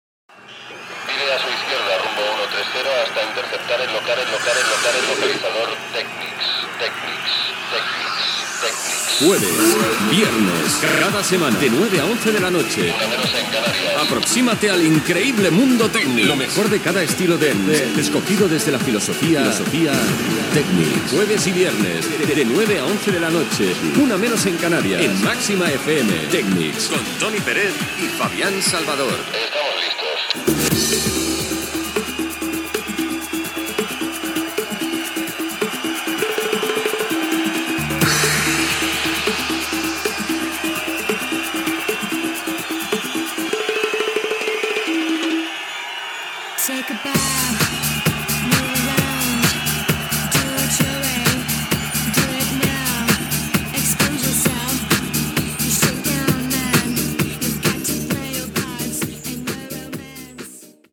Careta del programa i inici de la mescla musical
Musical